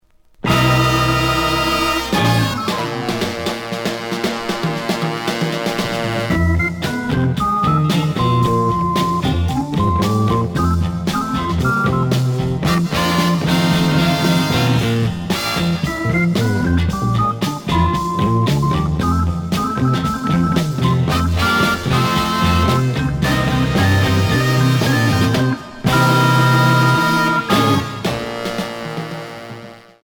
試聴は実際のレコードから録音しています。
●Format: 7 inch
●Genre: Rock / Pop